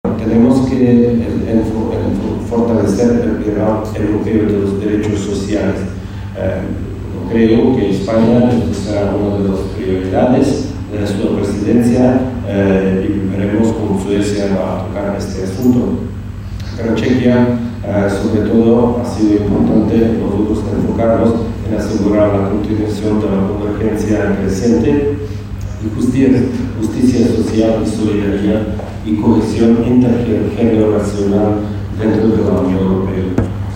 Embajadores, cónsules y consejeros de embajadas participaron en la sede ‘Por Talento Digital’, de Fundación ONCE, en el VII Encuentro Diplomacia para la Inclusión organizado por el Grupo Social ONCE y la Academia de la Diplomacia, bajo el patrocinio del embajador de la República Checa en nuestro país, que ostenta la presidencia semestral del Consejo de la UE.